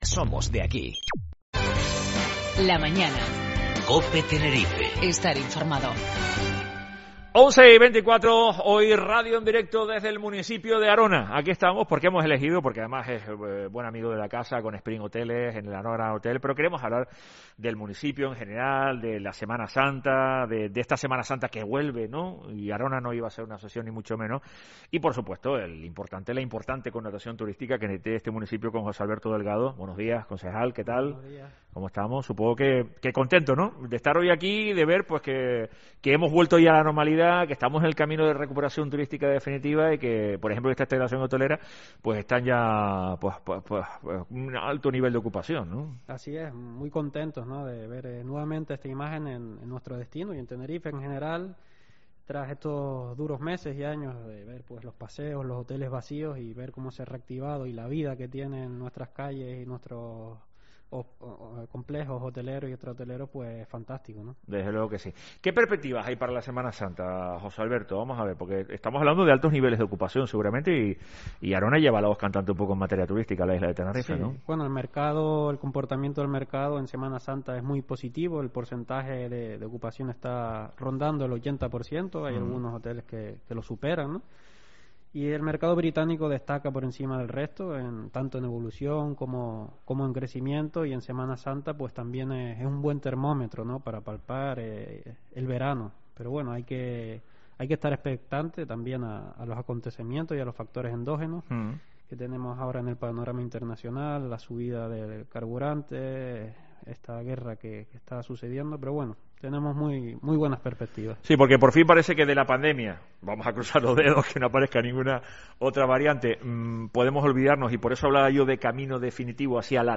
José Alberto Delgado, concejal de Turismo de Arona, en La Mañana en Tenerife desde Los Cristianos